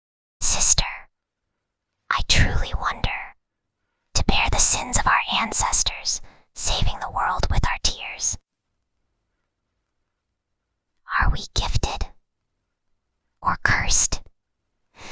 Whispering_Girl_3.mp3